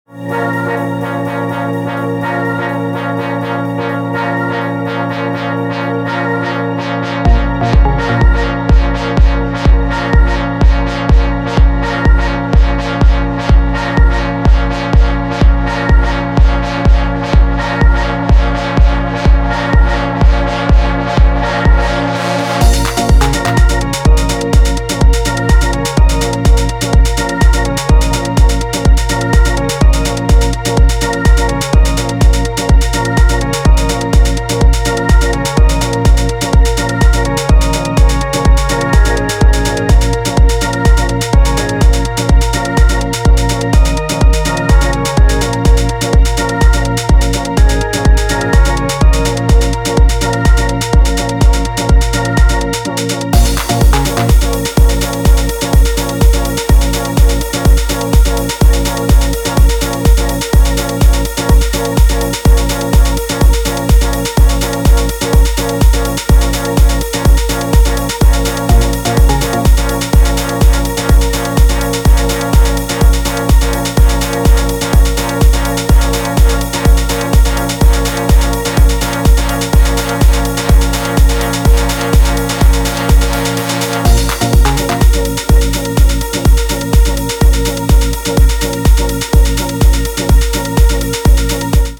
いずれの楽曲もピークタイムを熱く盛り上げてくれそうな仕上がりで、今回も渾身の内容ですね！